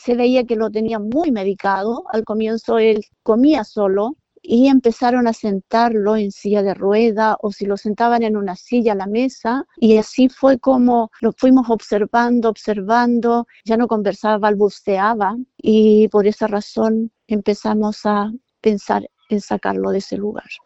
testimonio-hogar1.mp3